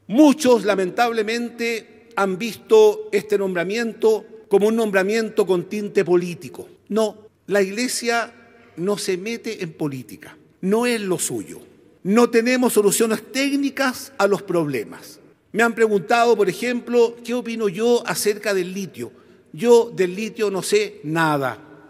En medio de una misa en la que asistieron figuras del mundo político, el Arzobispo Fernando Chomalí asumió su nuevo cargo en la Catedral Metropolitana de Santiago.
Durante su primera homilía, hizo una aclaración respecto a sus funciones.